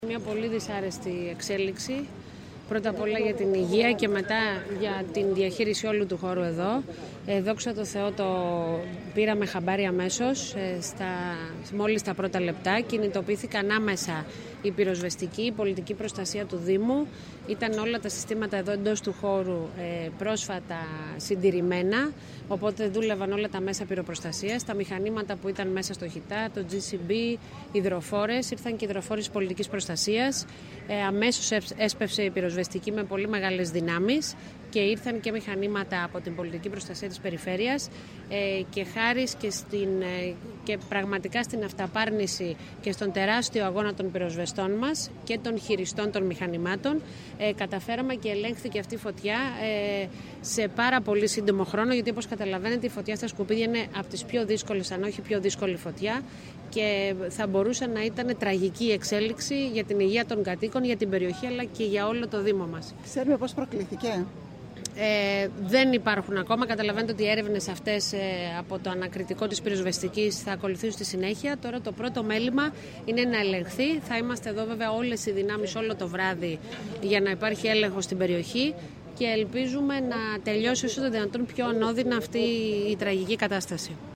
Ιδιαίτερα δυσάρεστη χαρακτήρισε την φωτιά στο ΧΥΤΑ η δήμαρχος της Κέρκυρας Μ. Υδραίου η οποία επεσήμανε ότι η επέμβαση της πυροσβεστικής αλλά και της πολιτικής προστασίας ήταν άμεση ενώ λειτούργησαν και τα συστήματα πυροπροστασίας του χώρου.